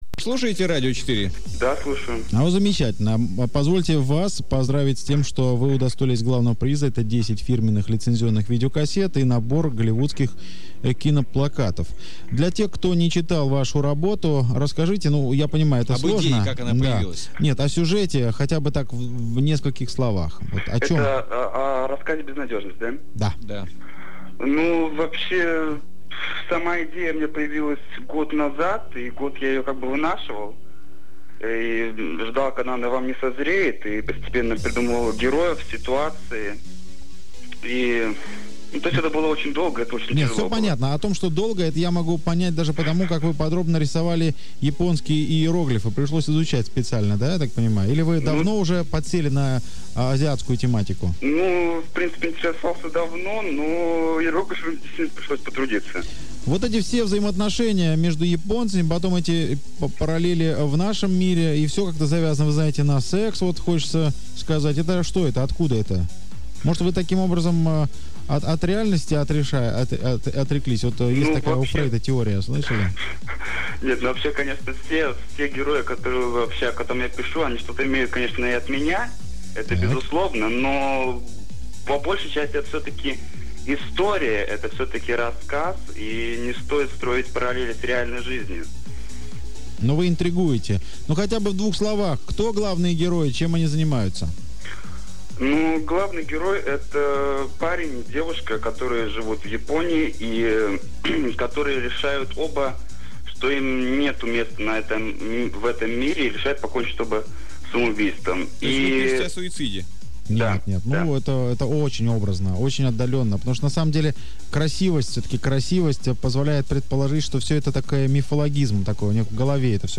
Это интервью я давал в октябре 2005-го года по телефону.
Это интервью длилось чуточку дольше и было более динамичным, но сначала меня застали врасплох, так как я совсем не ожидал своей победы, и не был готов к такому повороту событий.
Второе интервью. Первая часть - 3 мин 25 сек; 3,9 МB